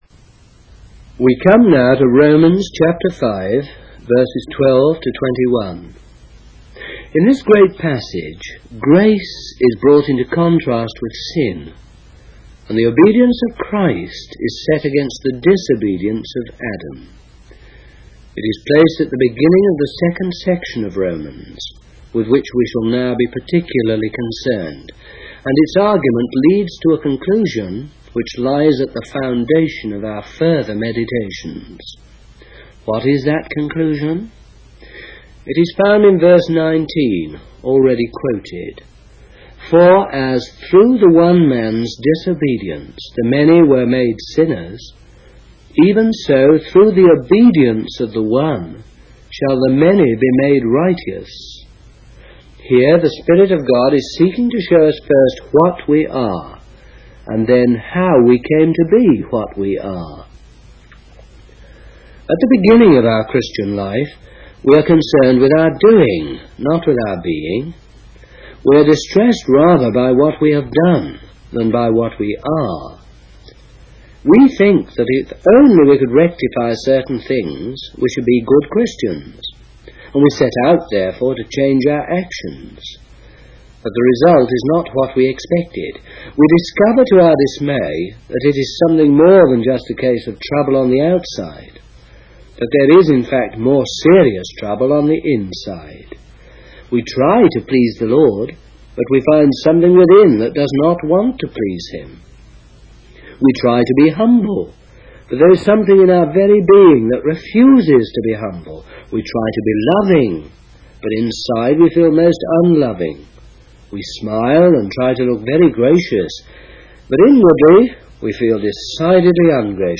In this sermon on Romans chapter 5, verses 12 to 21, the preacher discusses the contrast between grace and sin, and the obedience of Christ versus the disobedience of Adam. The main conclusion drawn from this passage is that through Adam's disobedience, many were made sinners, but through the obedience of Christ, many can be made righteous. The sermon emphasizes that our Christian life should not be focused on our own efforts to change our actions, but on recognizing that God has done it all for us.